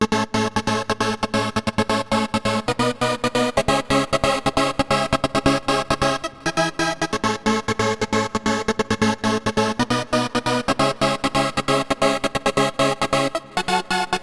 TI CK7 135 Det Chord2.wav